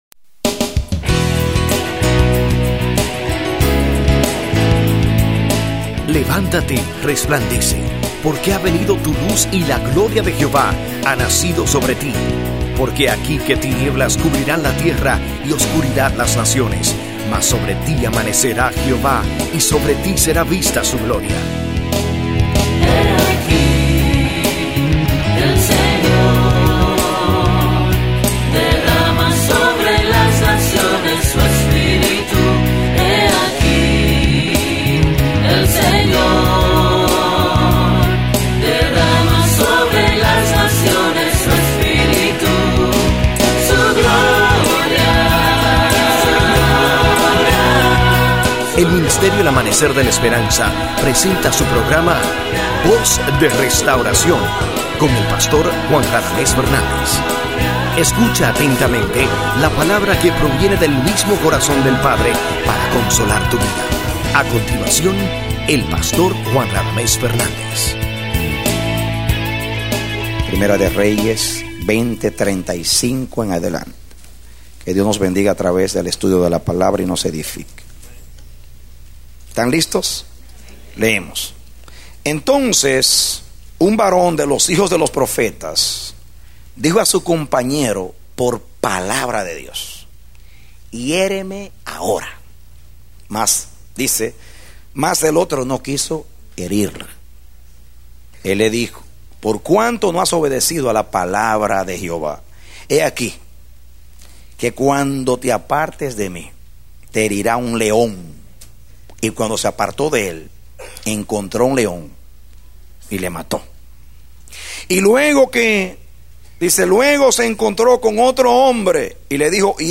Mensaje: “Puso Su Nombre”
A mensaje from the serie "Mensajes." Predicado Marzo 9, 2010